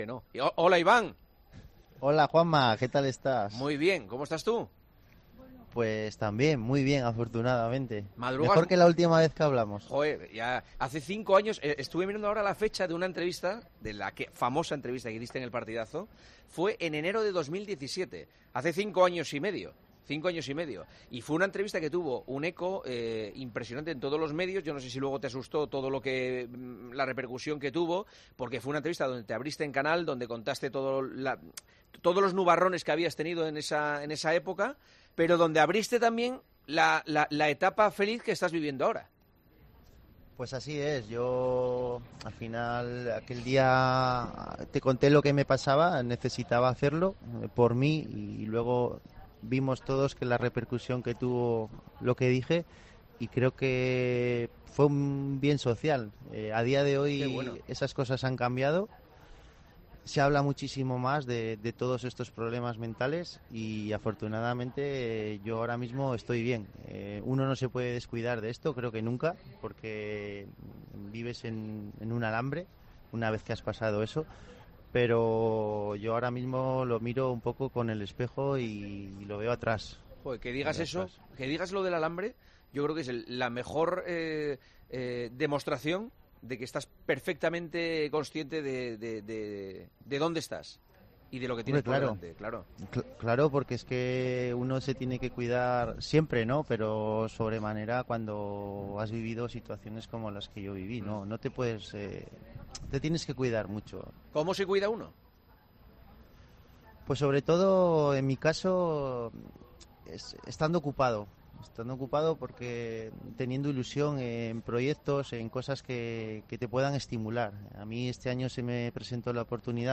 AUDIO: El subcampeón del mundo de contrarreloj nos habla ahora de su nueva labor como regulador de carreras.